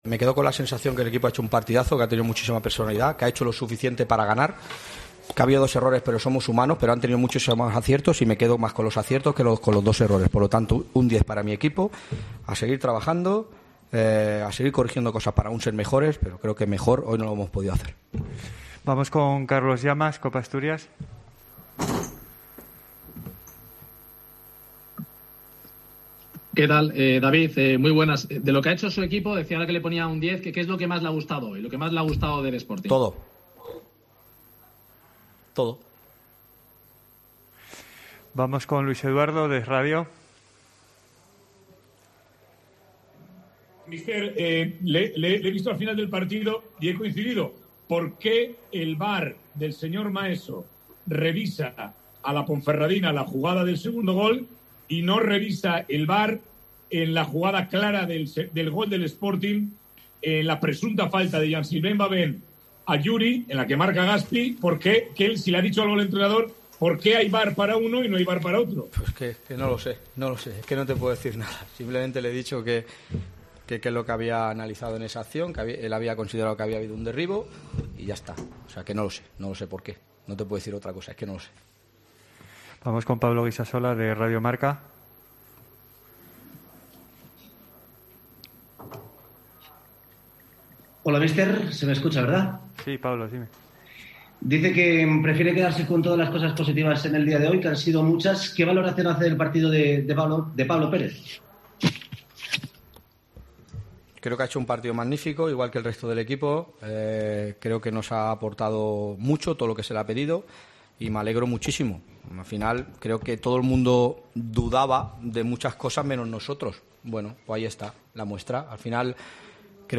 Escucha aquí las declaraciones del míster del Sporting de Gijón, David Gallego, y del entrenador de la Deportiva Ponferradina, Jon Pérez Bolo